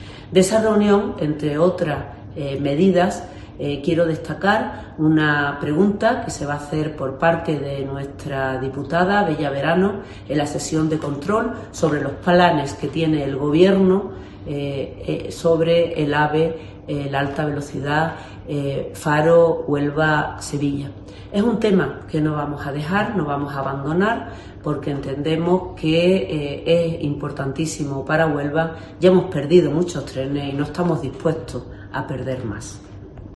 Pilar Miranda alcaldesa de Huelva